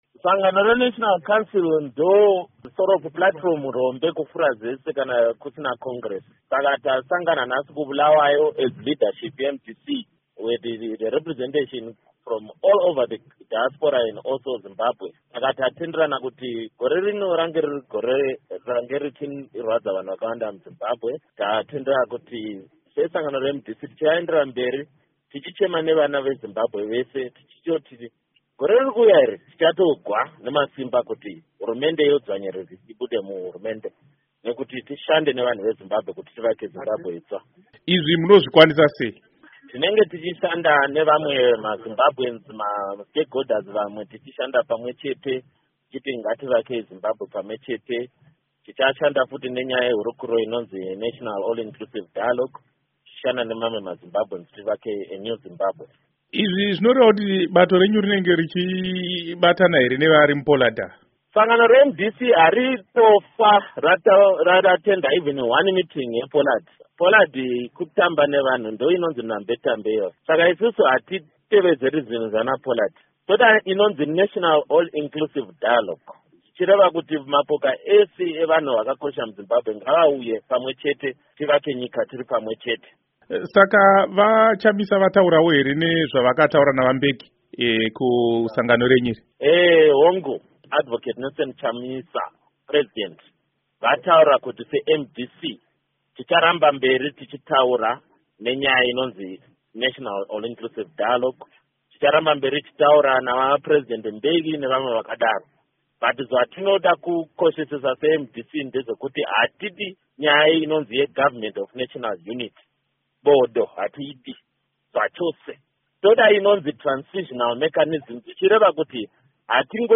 Hurukuro naVaFortune Daniel Molokele